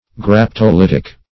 graptolitic - definition of graptolitic - synonyms, pronunciation, spelling from Free Dictionary
Search Result for " graptolitic" : The Collaborative International Dictionary of English v.0.48: Graptolitic \Grap"to*lit`ic\, a. Of or pertaining to graptolites; containing graptolites; as, a graptolitic slate.